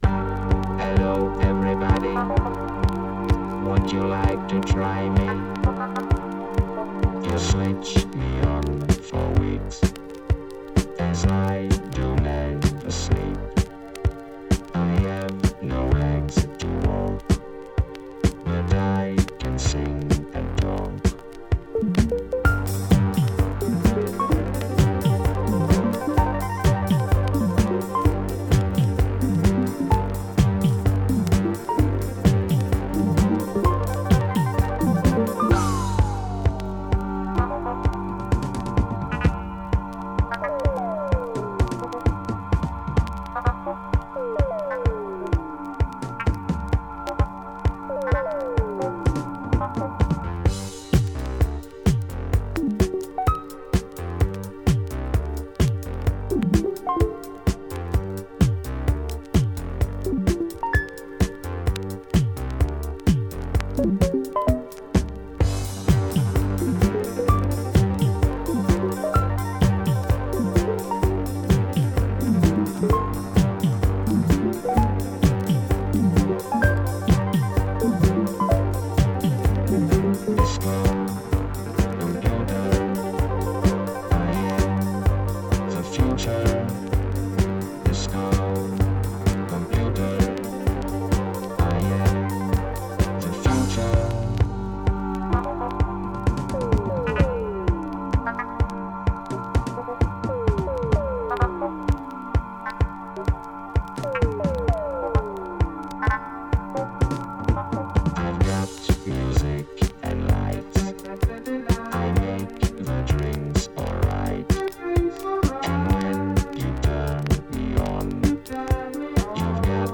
Filed under disco